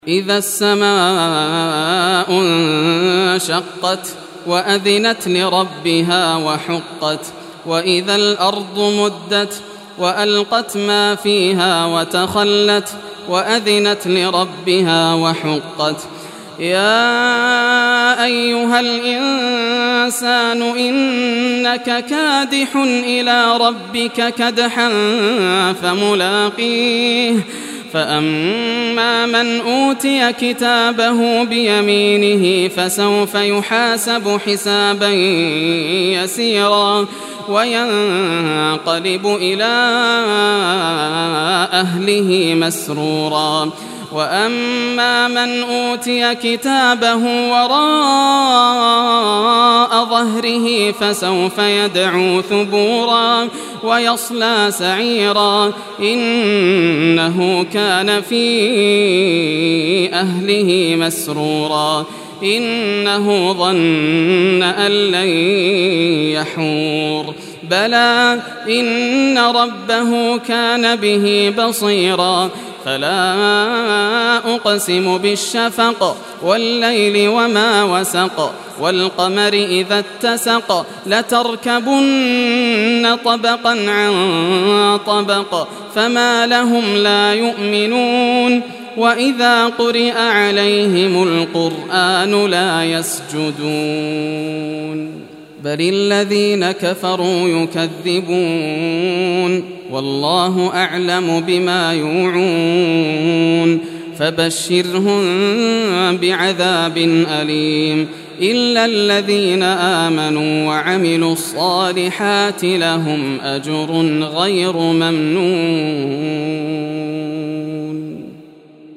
Surah Al-Inshiqaq Recitation by Yasser al Dosari
Surah Al-Inshiqaq, listen or play online mp3 tilawat / recitation in Arabic in the beautiful voice of Sheikh Yasser al Dosari.